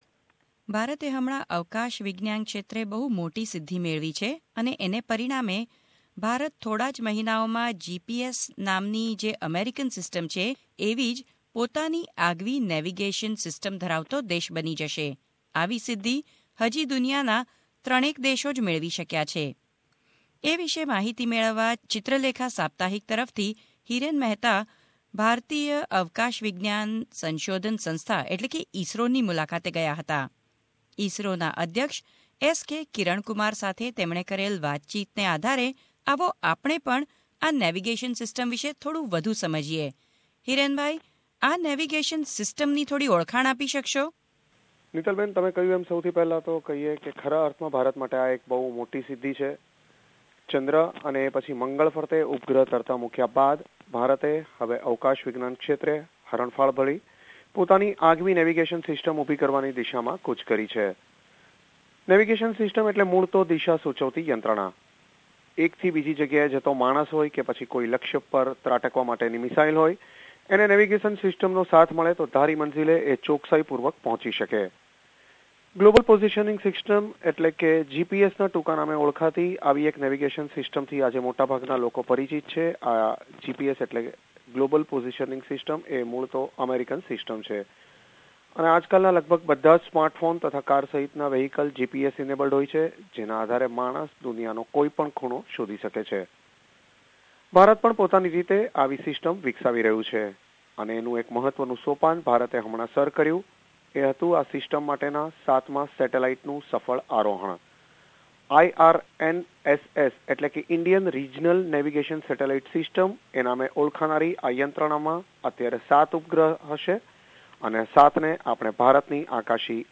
ISRO અધ્યક્ષ કિરણકુમાર સાથે વિગતે વાત કરી .